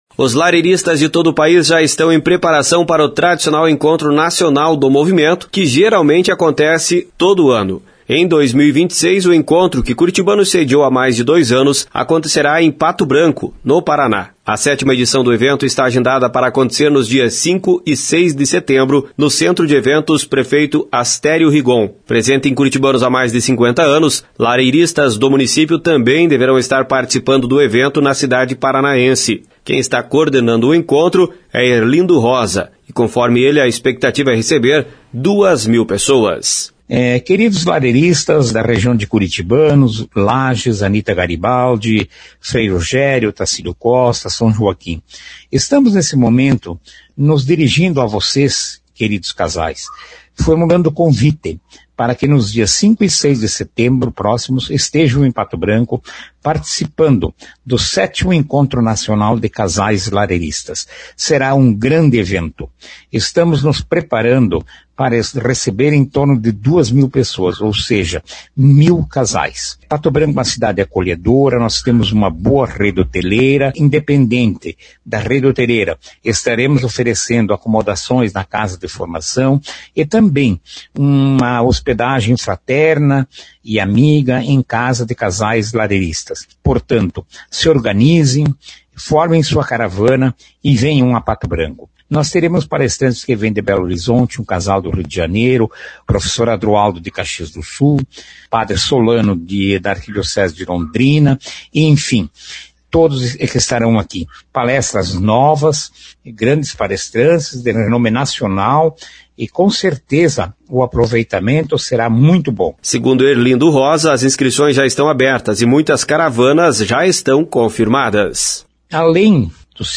Informações com o repórter